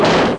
snd_20284_Thump5.wav